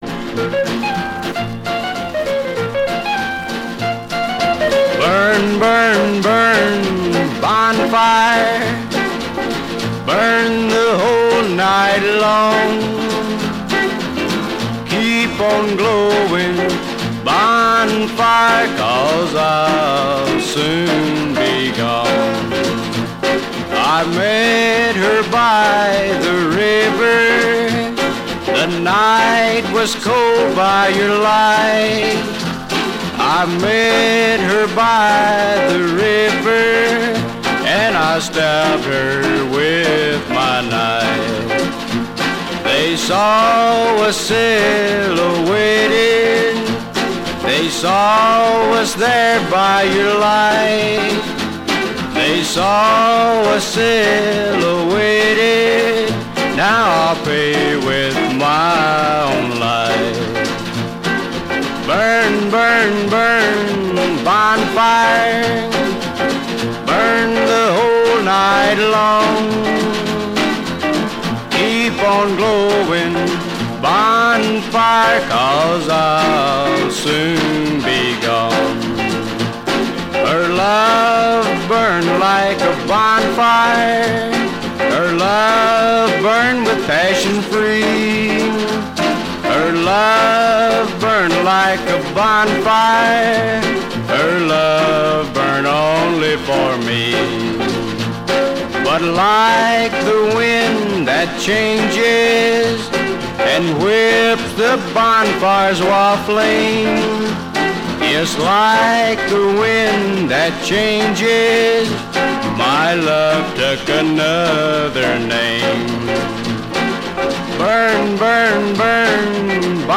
Америка. Мужской голос.
Если не ошибаюсь он был в Кантри-рок стиле .